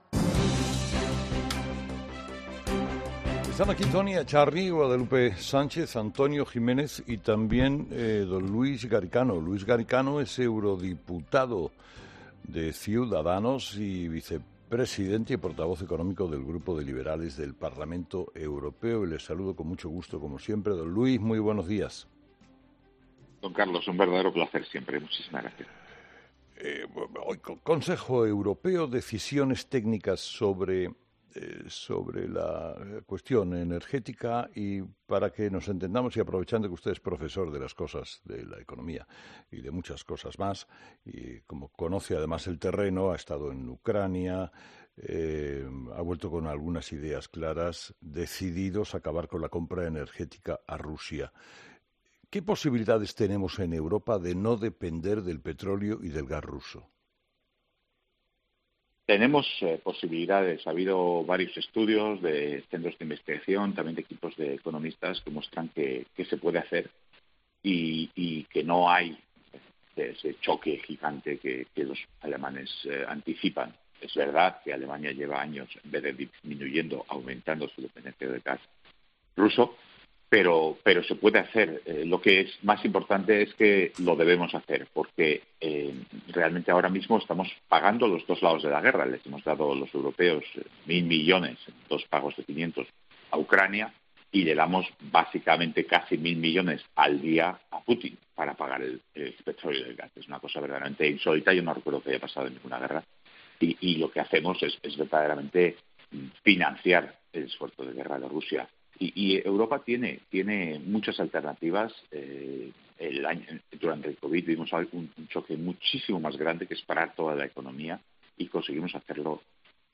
Uno de los hombres que participa de manera activa en las decisiones que se toman en política europea, es el eurodiputado de Ciudadanos Luis Garicano ; que en esta mañana de viernes, ha pasado por los micrófonos de Herrera en COPE , para responder a las preguntas del propio Carlos Herrera y de su equipo de tertulianos.